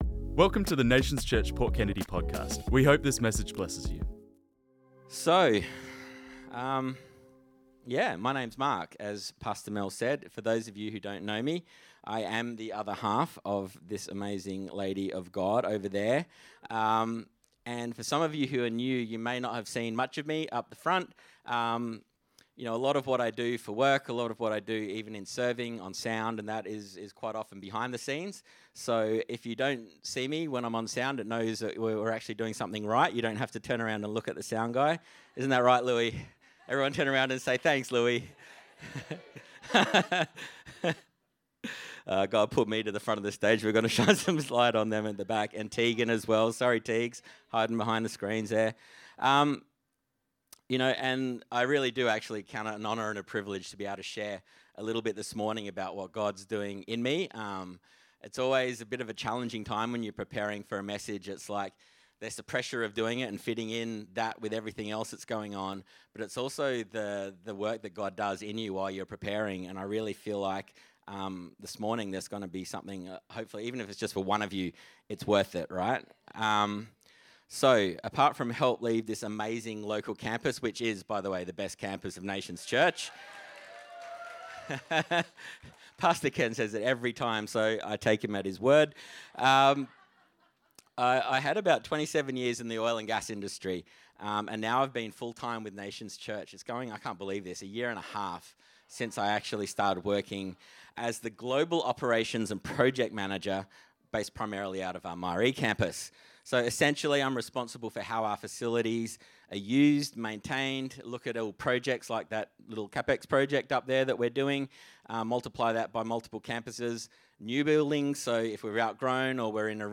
This message was preached on Sunday 9th February 2025